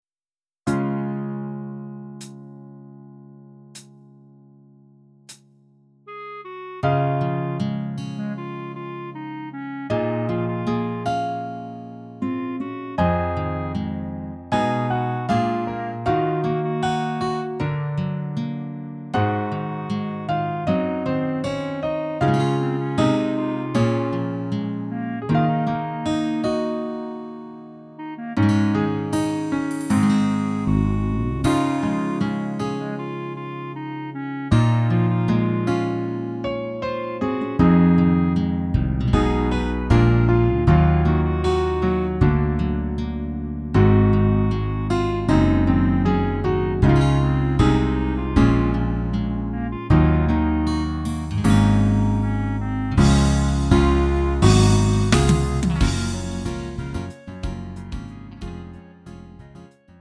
음정 원키 3:45
장르 구분 Lite MR